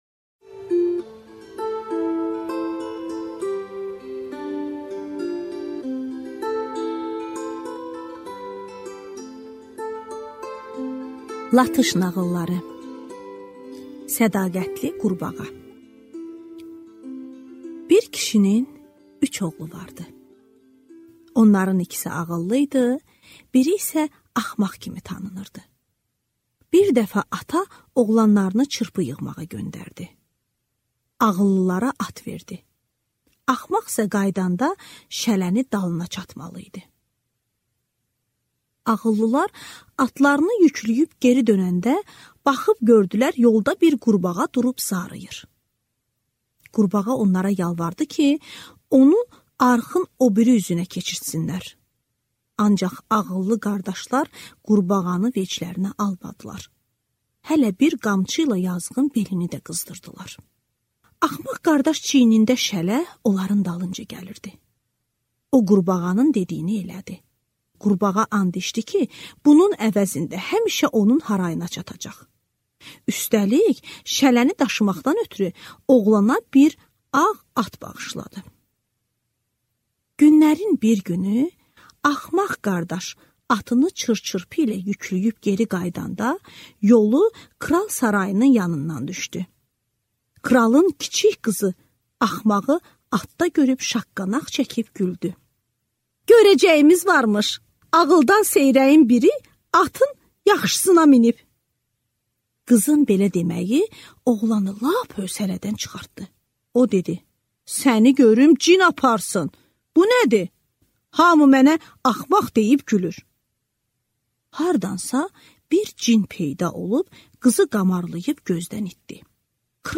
Аудиокнига Latış nağılları | Библиотека аудиокниг